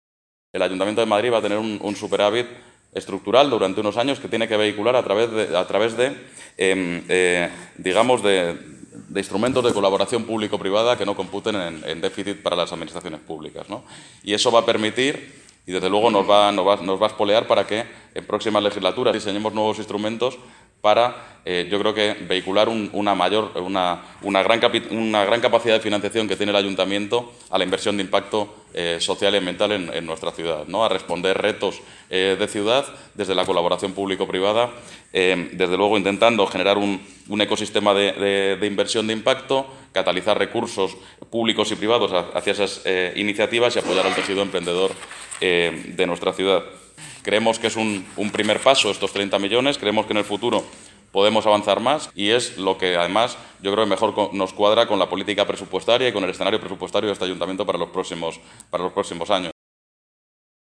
Jorge García Castaño participa en la presentación del informe organizada por Impact Hub Madrid